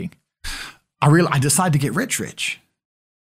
Andrew Tate I DECIDED TO GET RICH RICH sound effect download for free mp3 soundboard online meme instant buttons online